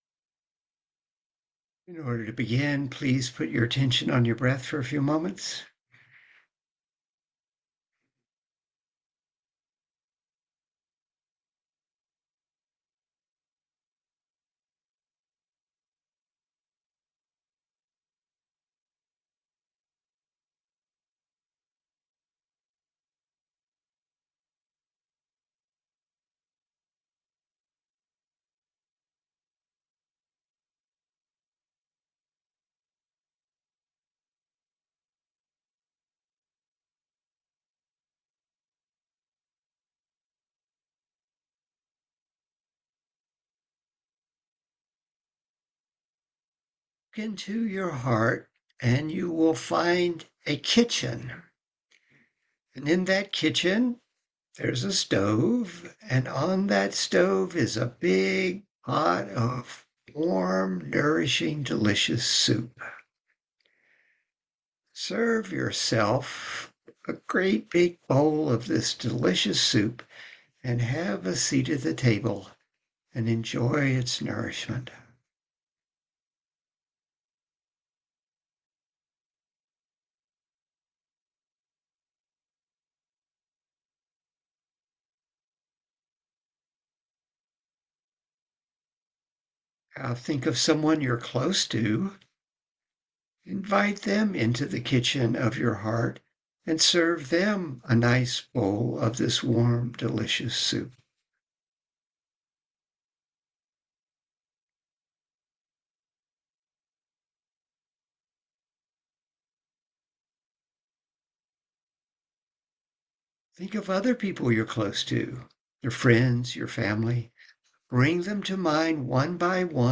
MP3 of the guided Mettā visualization